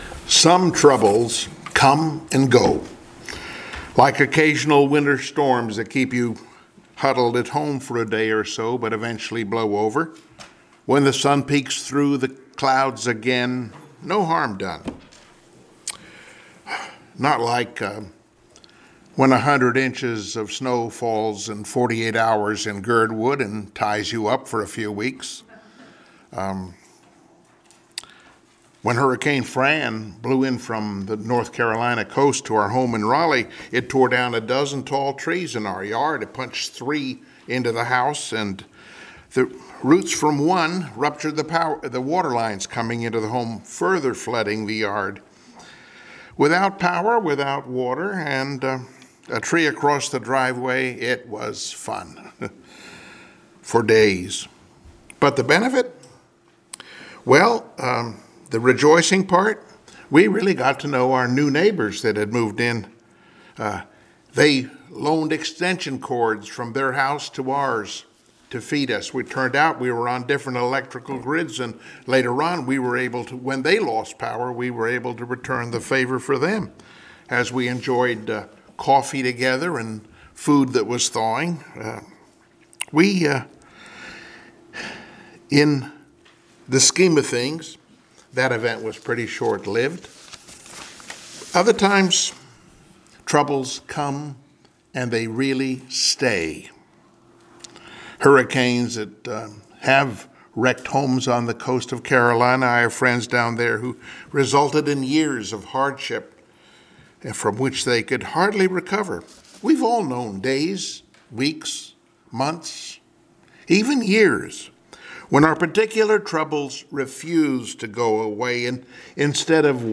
Passage: James 1:2-3 Service Type: Sunday Morning Worship